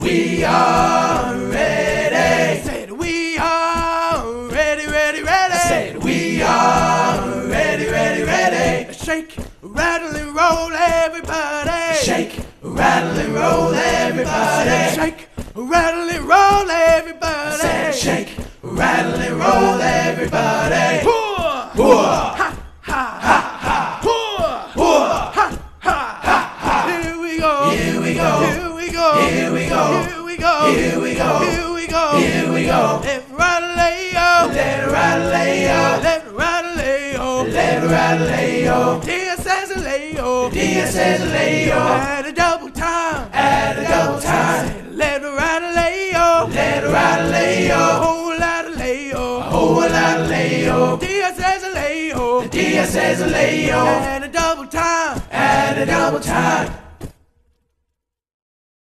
⚠ — Enjoy this old classic Army Running Cadence!